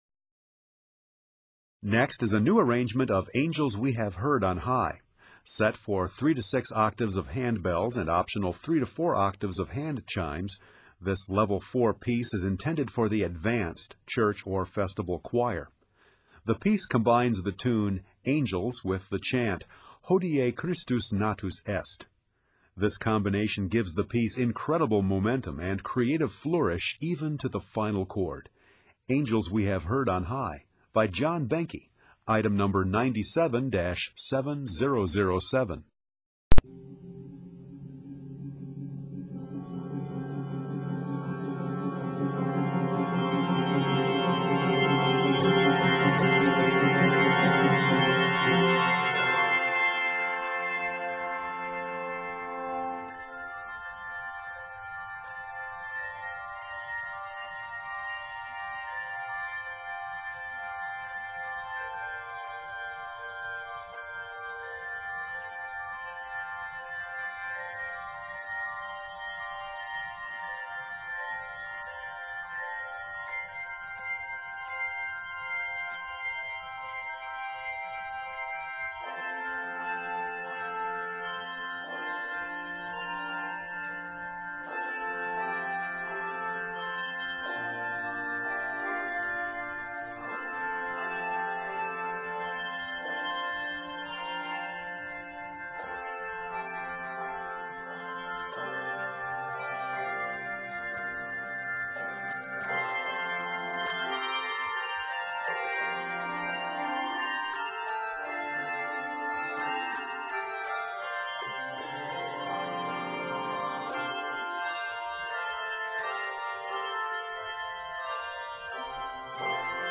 Traditional French carol Arranger